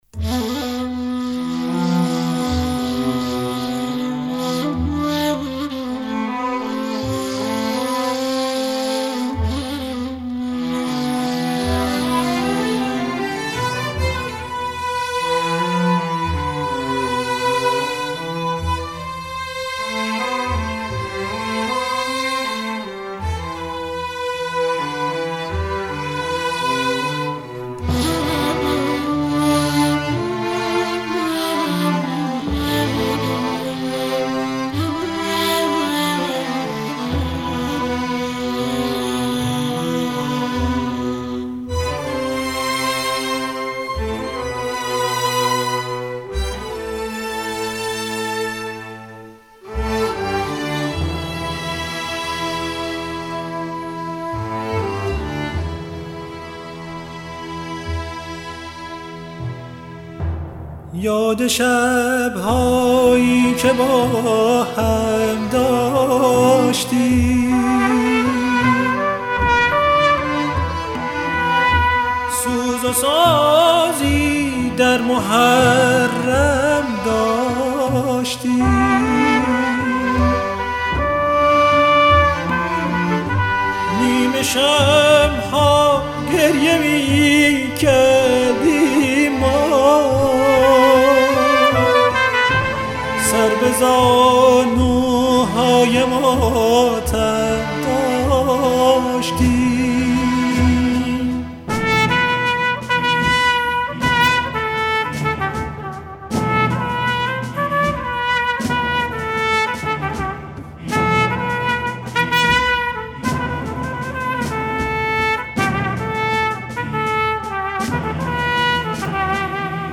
اثری است ارکسترال